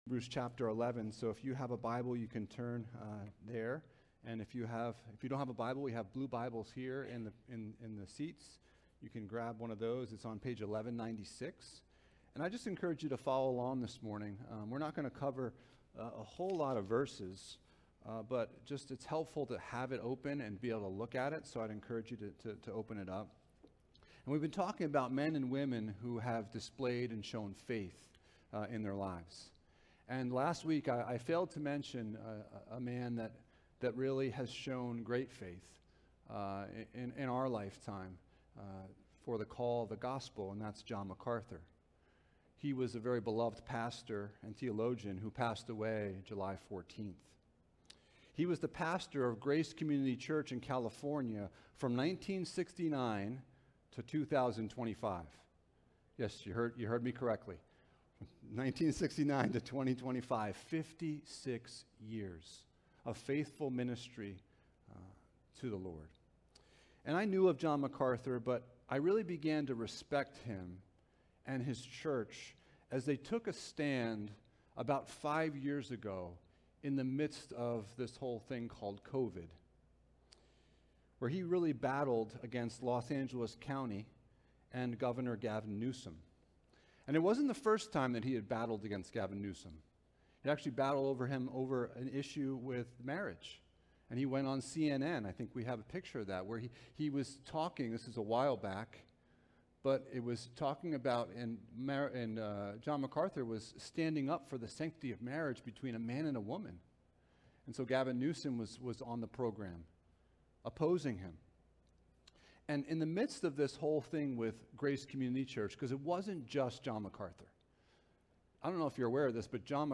Jesus is Better Passage: Hebrews 11: 32-40 Service Type: Sunday Morning « Leaving a Legacy of Faith The Lord’s Discipline